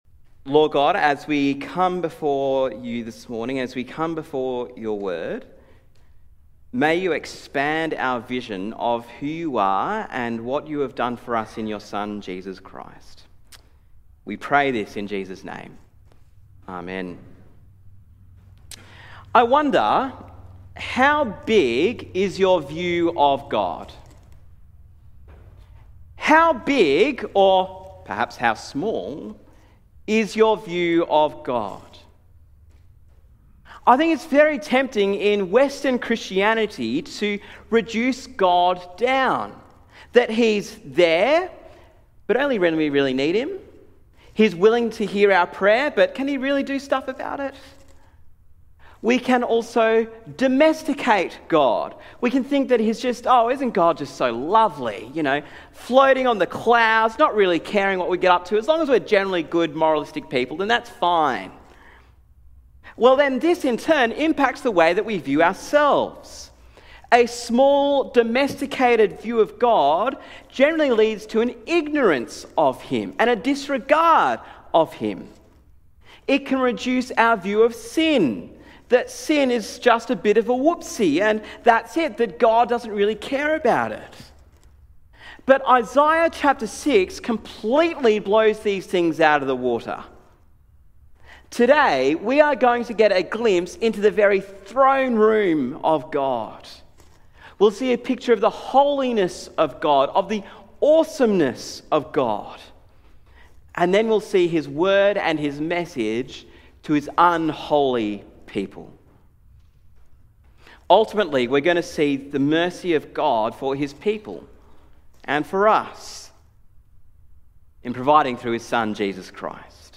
Sermon on Isaiah 6 - The Holiness of God